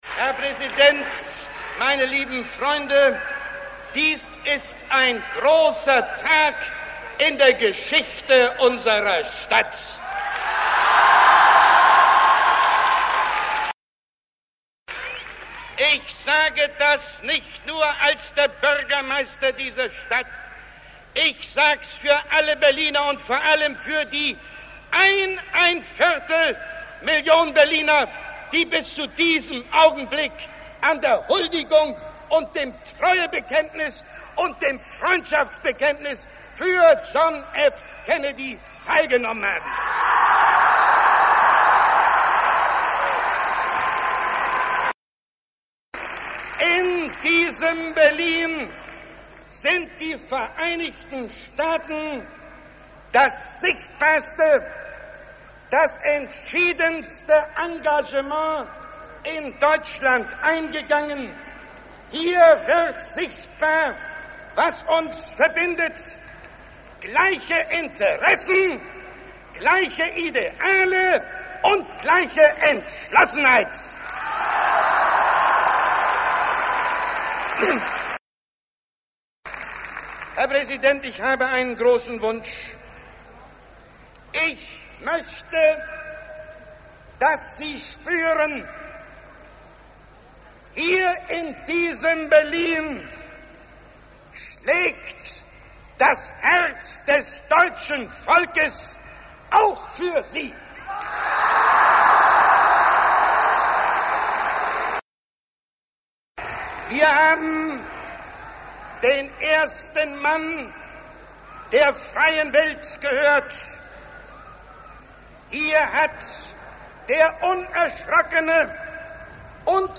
Audioausschnitt aus der Begrüßungsansprache des Regierenden Bürgermeisters Willy Brandt am Rathaus Schöneberg am 26. Juni 1963.
Ausschnitt aus der Begrüßungsansprache des Regierenden Bürgermeisters